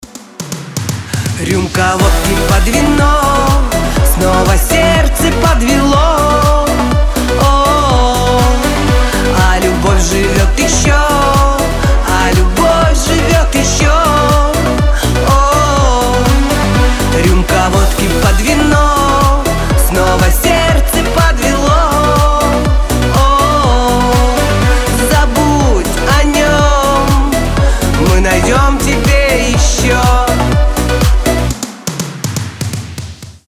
застольные
женские